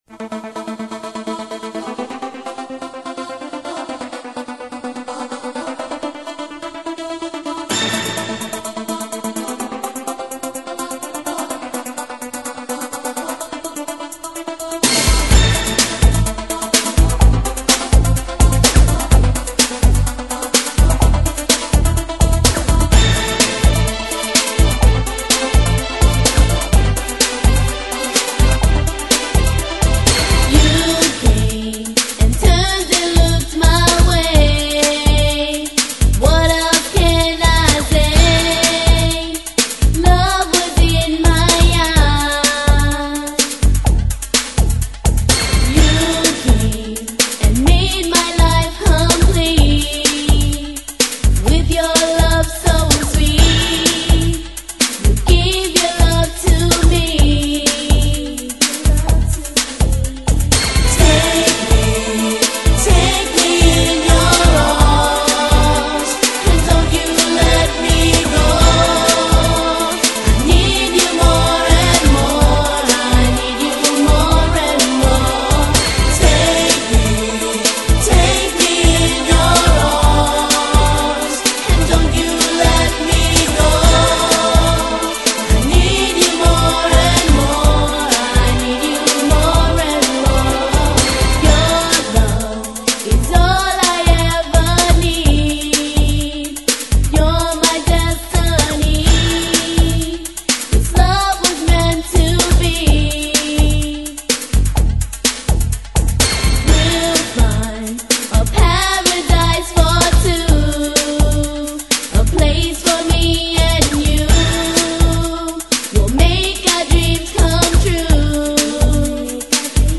Dance Para Ouvir: Clik na Musica.